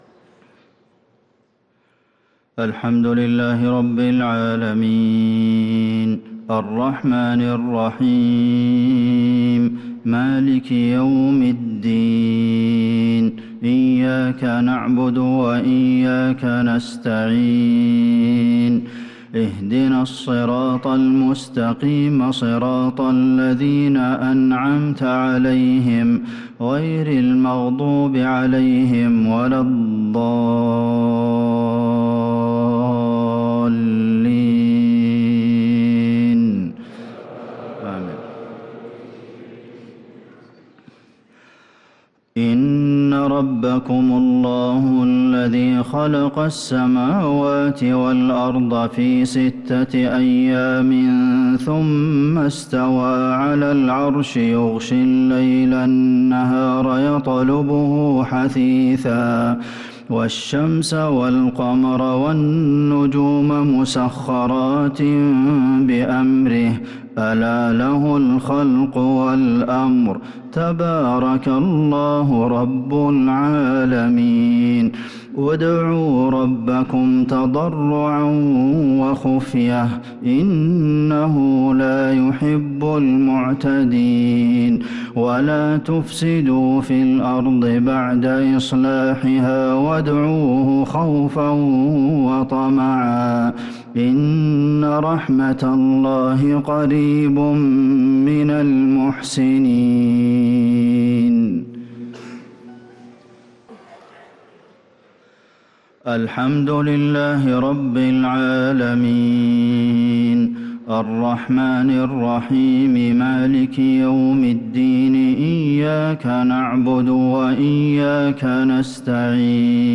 صلاة المغرب للقارئ عبدالمحسن القاسم 12 ربيع الأول 1444 هـ
تِلَاوَات الْحَرَمَيْن .